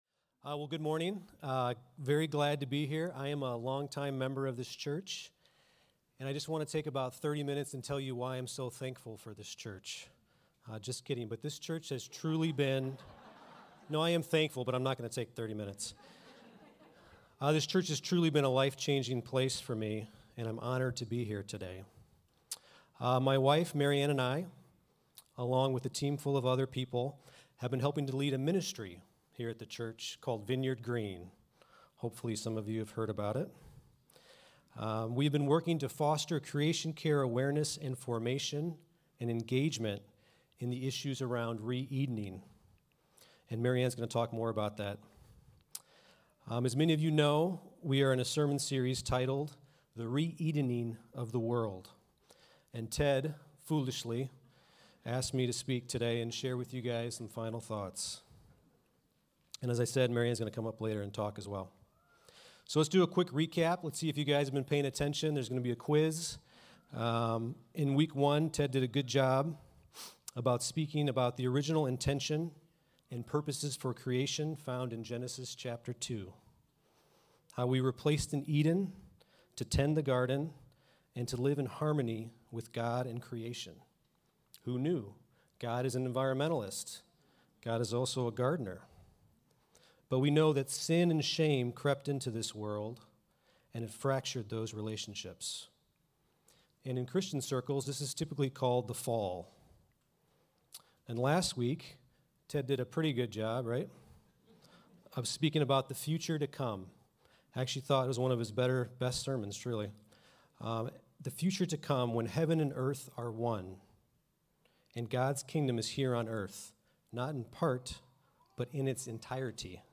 Evanston Vineyard